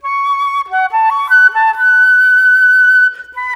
Rock-Pop 01 Flute 02.wav